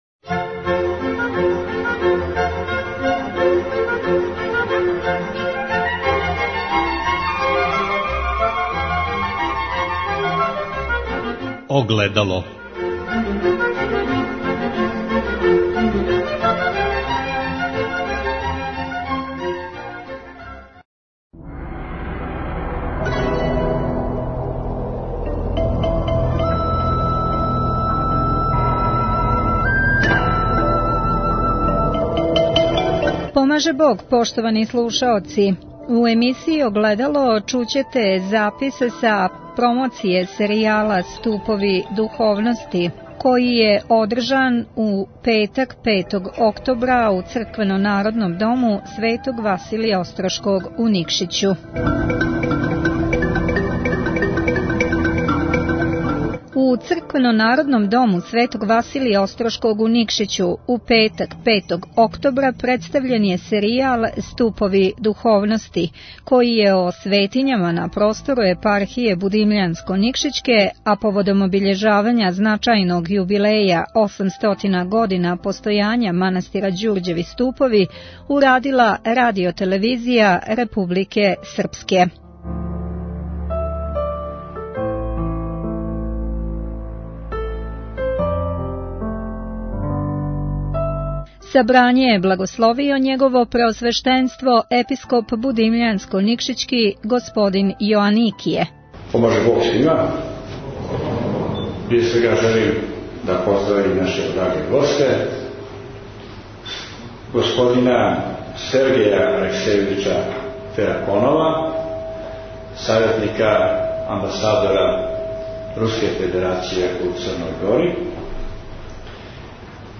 У Црквено - народном дому Светог Василија Острошког у Никшићу, у петак 5. октобра представљен је серијал „Ступови духовности", који је о светињама на простору Епархије будимљанско - никшићке, а поводом обиљежавања значајног јубилеја - 800 година постојања манастира Ђурђеви Ступови, урадила РТВ Републике Српске.